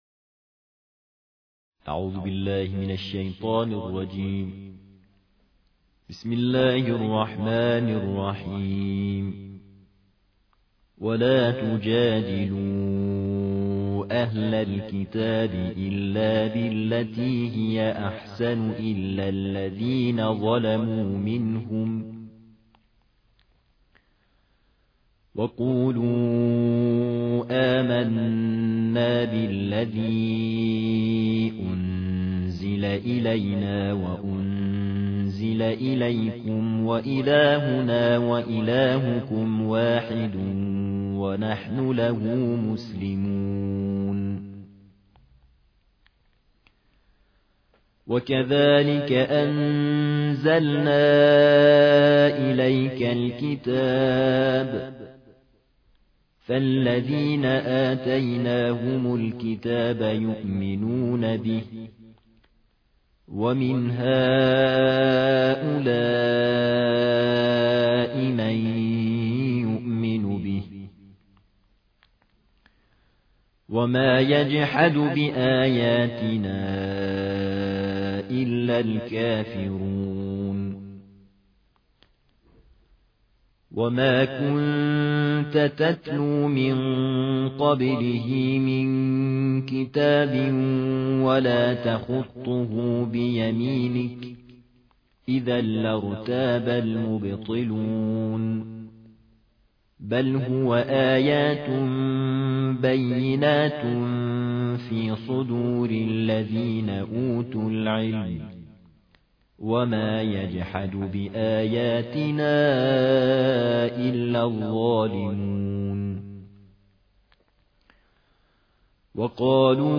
ترتیل جزء ۲1 قرآن کریم + دانلود